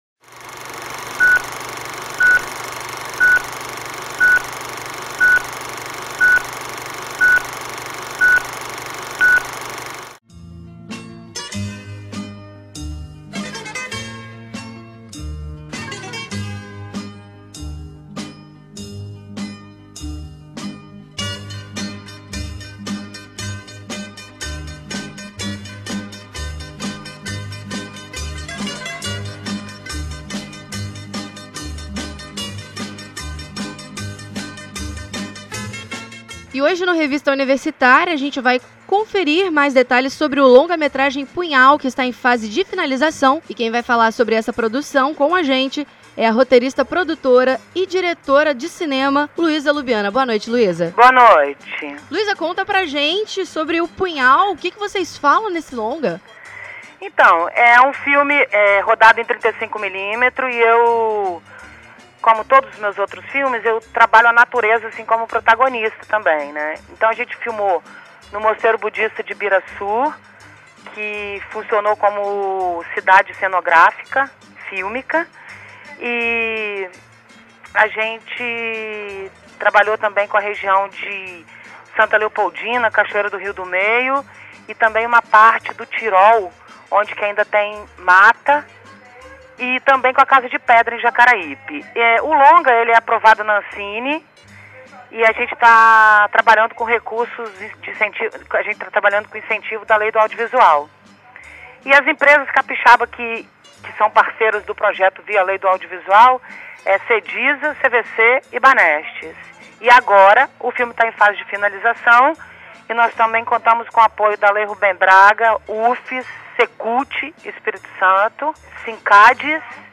Então confira um bate-papo